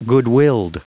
Prononciation du mot goodwilled en anglais (fichier audio)
Prononciation du mot : goodwilled